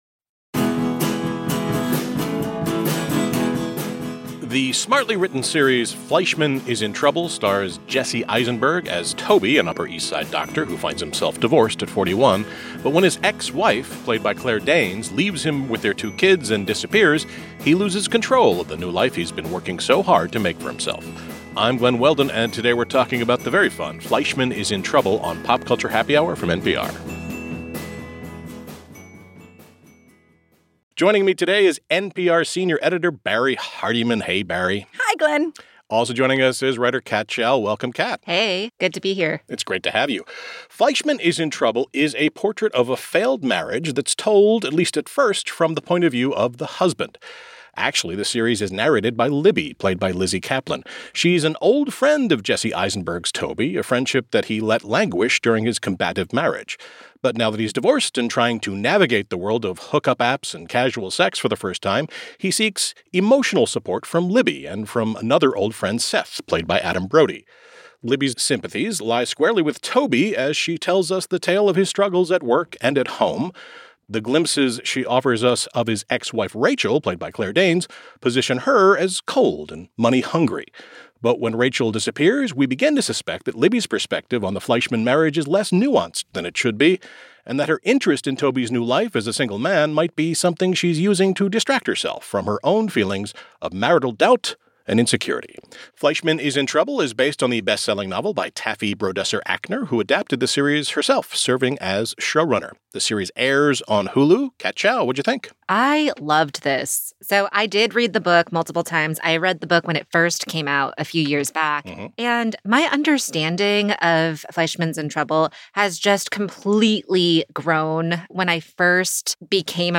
TV Review